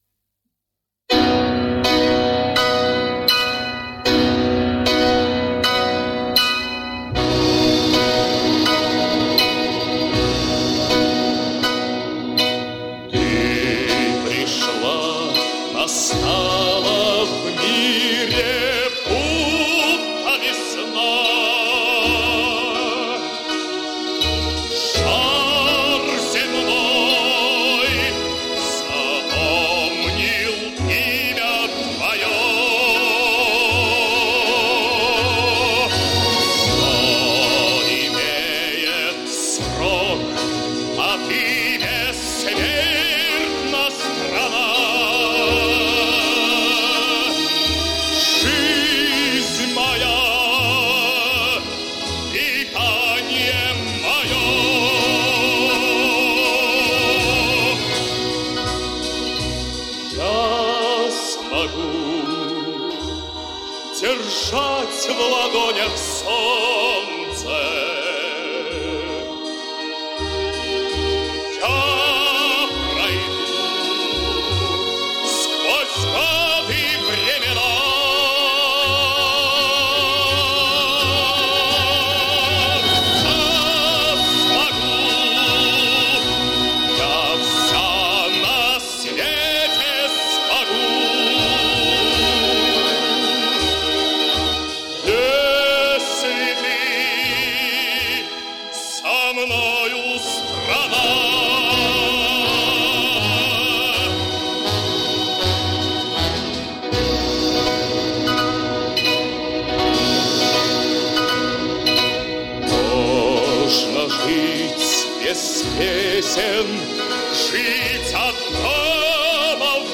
Найдена среди старых пластинок.
Патриотическая песня, славящая СССР.